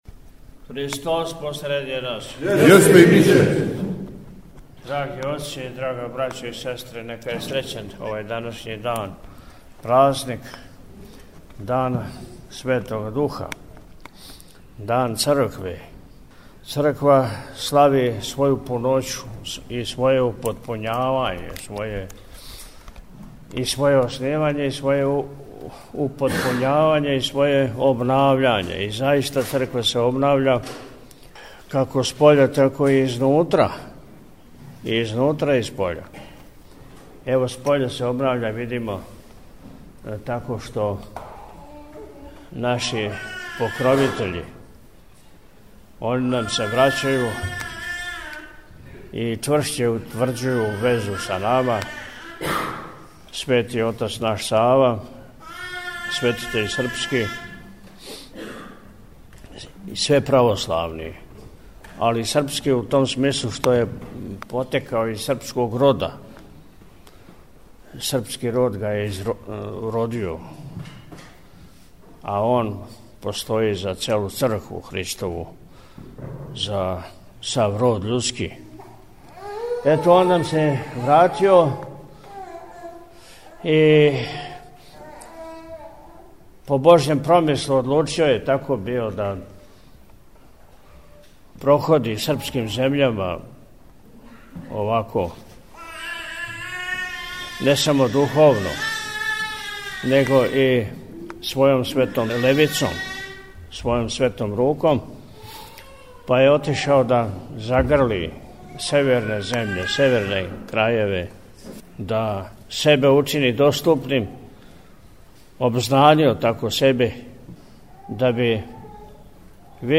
Присутном верном народу Високопреосвећени се обратио краћом пастирском поуком, у којој је поред осталог рекао: – Црква се обнавља, како споља тако и изнутра.